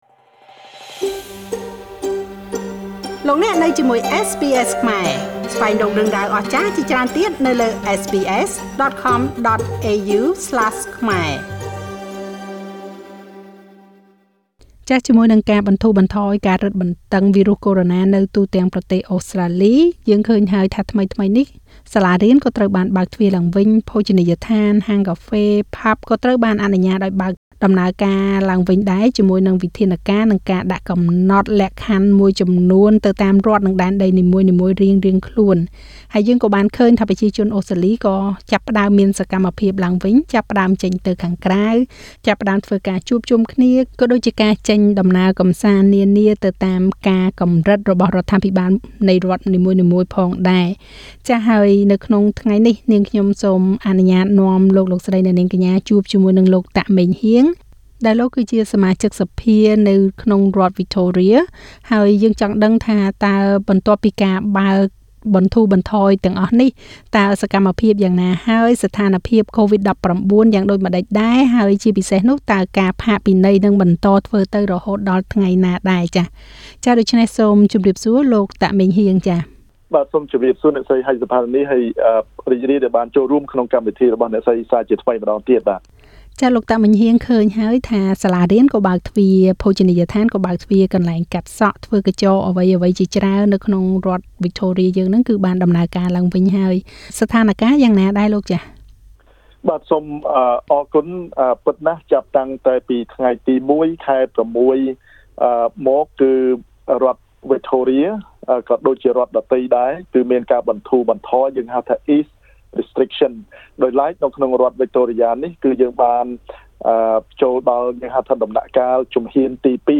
ជាមួយនឹងការបន្ធូរបន្ថយការរឹតបន្តឹងវីរុសកូរ៉ូណានៅទូទាំងប្រទេសអូស្រ្តាលី សាលារៀន ភោជនីយដ្ឋាន ហាងកាហ្វេ តៀមស្រា ត្រូវបានអនុញ្ញតិឲ្យបើកទ្វារដំណើរការឡើងវិញ ជាមួយនឹងវិធានការនិងការកំណត់លក្ខខណ្ឌមួយចំនួនទៅតាមរដ្ឋនិងដែនដីនីមួយៗ។ សូមស្តាប់បទសម្ភាសន៍ជាមួយលោក តាក ម៉េងហ៊ាង សមាជិកសភានៃរដ្ឋវិចថូរៀ ស្តីពីស្ថានភាពកូវីដ-១៩នៅក្នុងរដ្ឋនេះ ហើយថាតើការរឹតបណ្តឹង ការផាកពិន័យនឹងបន្តរហូតដល់ថ្ងៃណា។